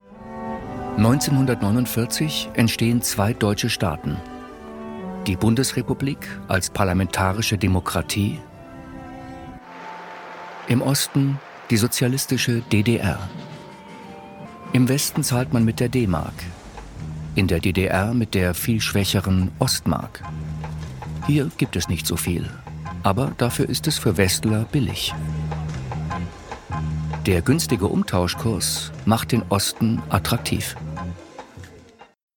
kommentar-zdf-75 jahre deutschland
75 Jahre Deutschland – Wir Grenzgänger als Erzähler mitgewirkt. Im Mittelpunkt der Doku stehen Menschen, in deren Leben sich die Geschichte des geteilten Landes spiegelt: Prominente wie die Musiker Reinhard Mey und Frank Schöbel aber auch Unbekannte, die versuchten, die Grenze zu überwinden oder mit der Spaltung zu leben.